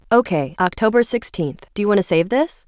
• 위에 음성이 Original, 아래 음성이 Reconstruction한 음성입니다.